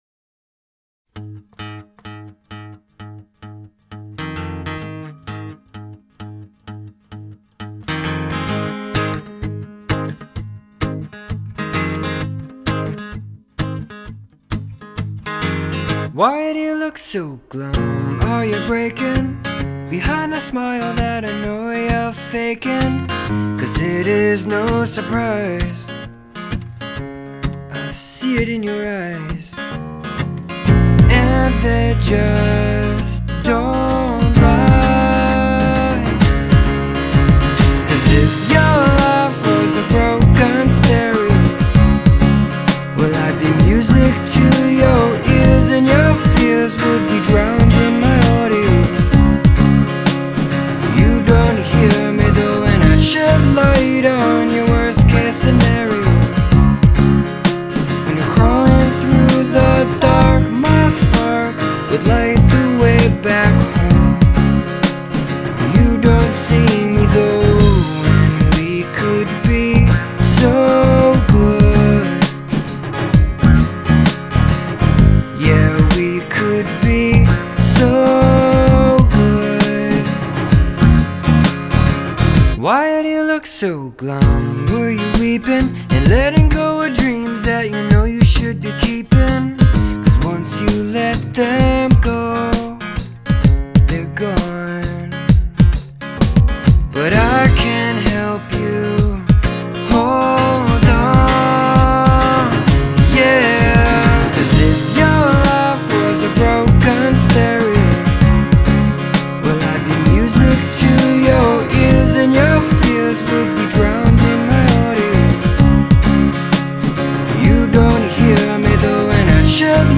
• Genre: Pop
Akustikversion